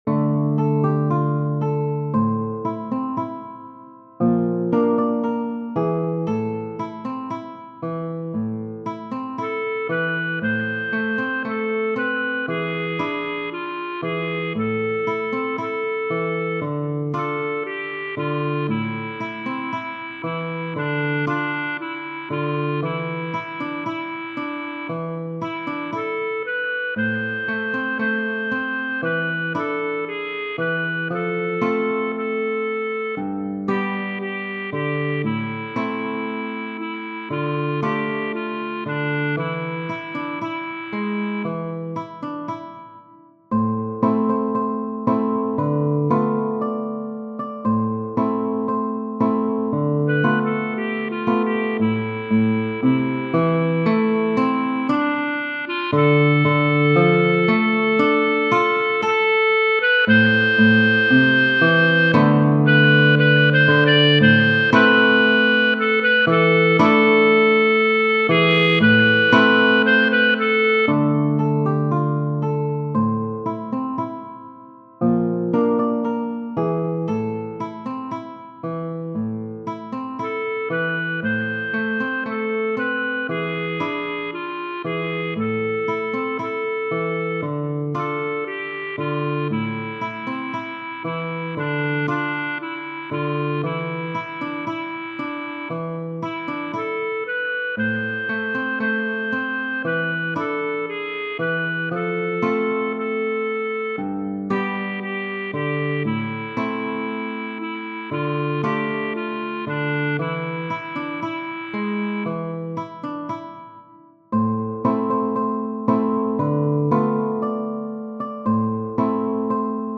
De Curtis, E. Genere: Napoletane Testo di L. Bovio, musica di E. De Curtis.